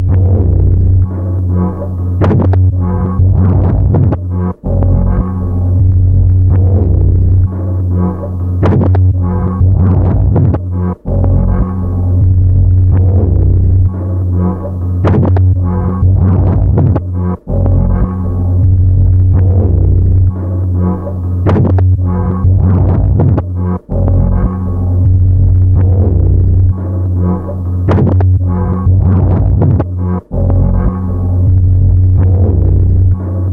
金属打击乐的卷对卷磁带循环录音 " Hum Frogger
描述：金属混响与间歇性爆裂和磁带操作记录在1/4"磁带上，被切割和物理循环
Tag: 金属混响 磁带操纵 流行 切好的 带环